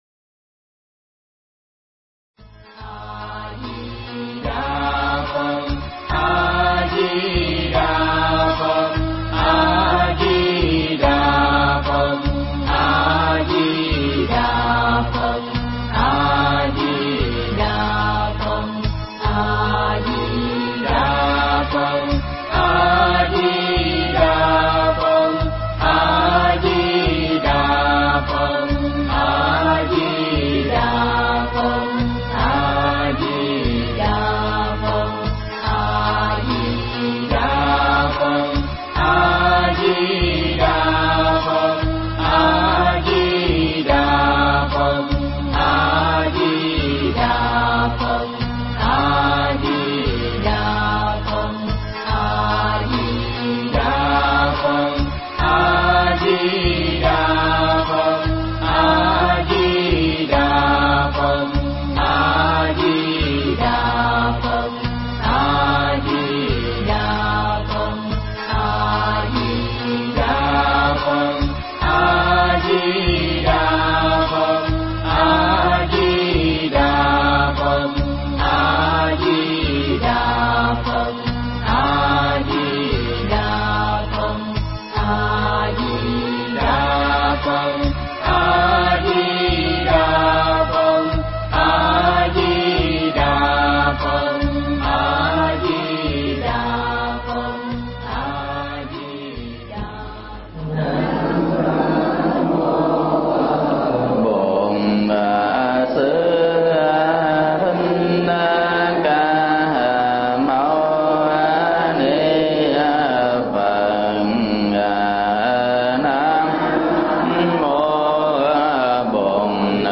Mp3 Thuyết Pháp Niệm Phật với tâm Hữu hạn và Vô hạn